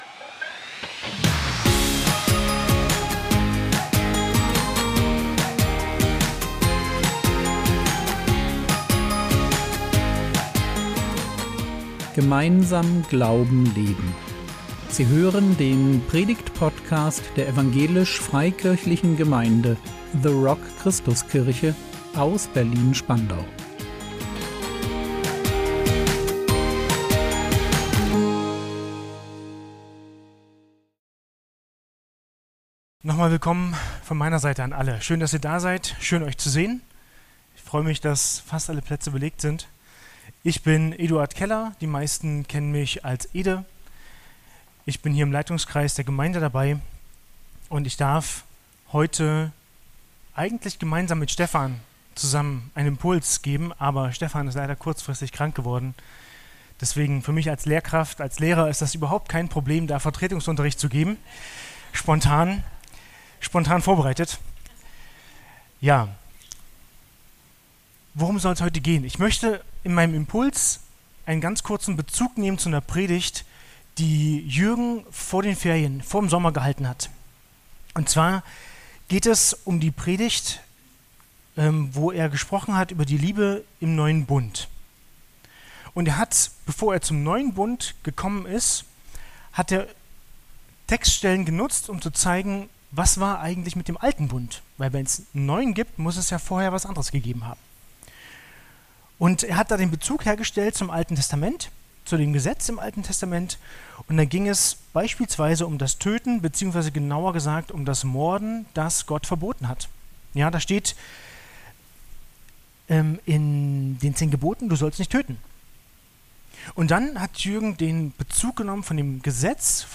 Praktisch Liebe leben | 14.09.2025 ~ Predigt Podcast der EFG The Rock Christuskirche Berlin Podcast